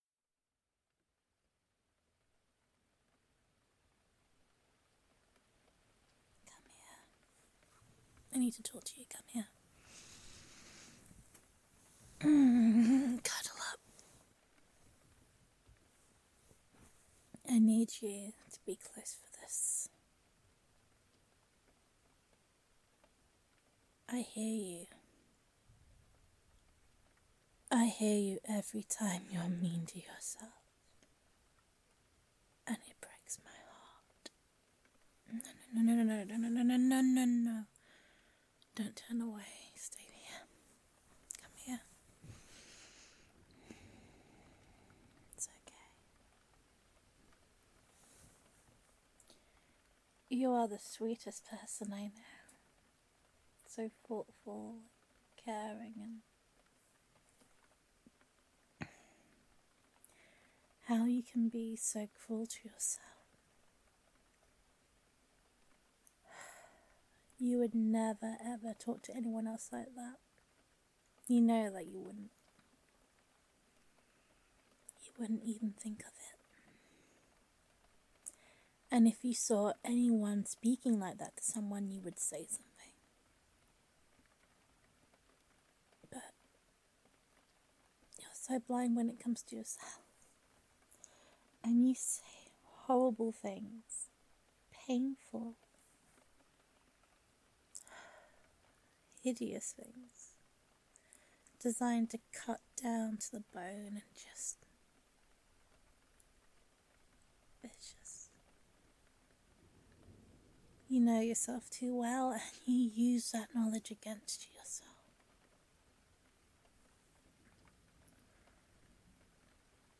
[F4A] You Will See [Cuddlesome][I Wish You Could See Yourself Like I See You][Comfort][Reassurance][Please Be Kind to Yourself][Gender Neutral][Comforting Loving Girlfriend Roleplay]